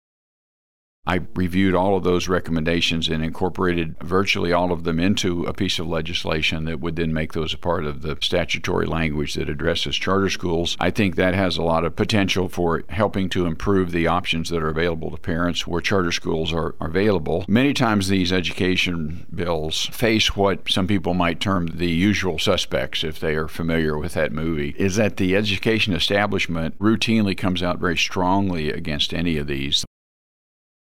JEFFERSON CITY — State Sen. Ed Emery, R-Lamar, discusses Senate Bill 349, legislation that seeks to require each local school district and charter school to have a policy for reading intervention plans for any pupils in grades kindergarten through four; Senate Bill 271, a measure that would transfer the authority of the State Board of Education and the Department of Elementary and Secondary Education to regulate charter schools to the Missouri Charter Public School Commission; and Senate Bill 160, which would establish the Missouri Empowerment Scholarship Accounts Program.